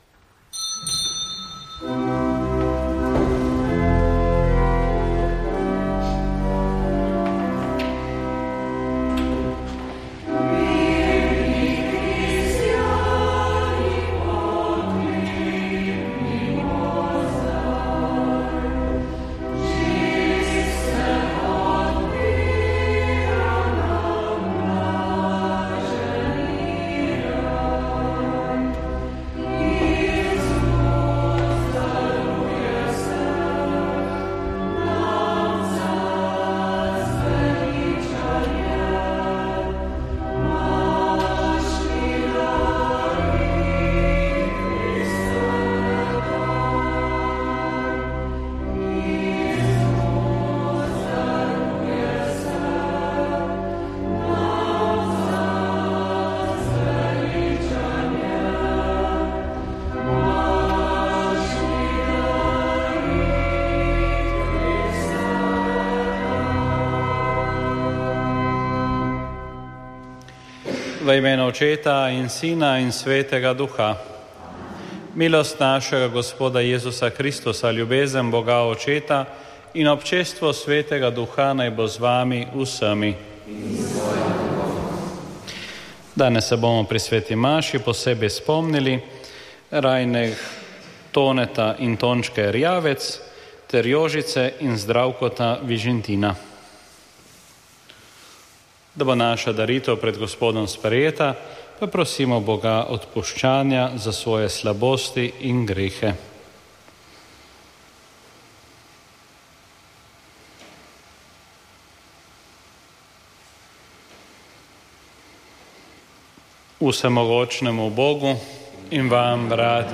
Sveta maša
Sv. maša iz bazilike Marije Pomagaj na Brezjah 17. 5.